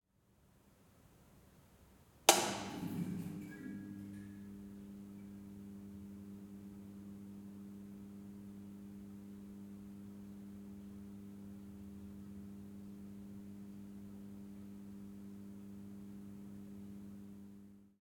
MECHSwtch_School  Classroom - Lights - SCL02, Switching On, Middle Distance_WWA_QSRT_Schoeps MK4_ORTF.ogg